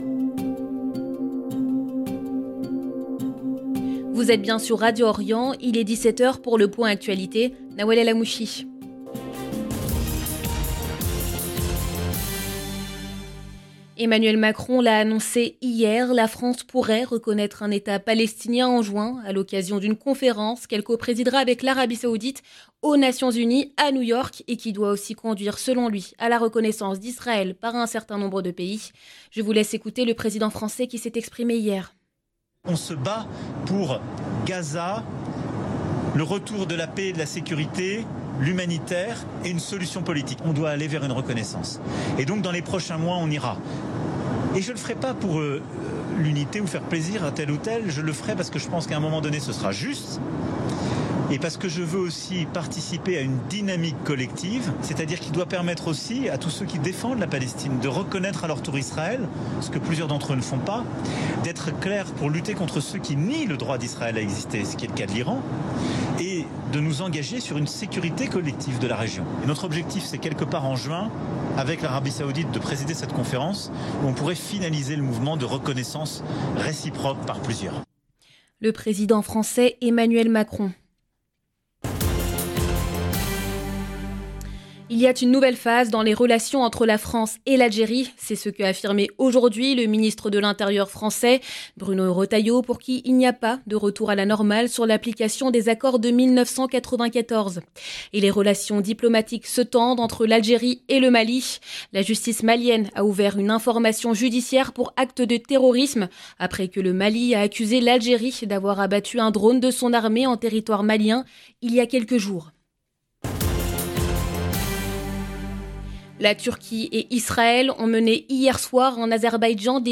LE JOURNAL EN LANGUE FRANCAISE DU SOIR 10/04/2025